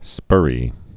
(spûrē, spŭrē)